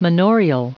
Prononciation du mot manorial en anglais (fichier audio)
Prononciation du mot : manorial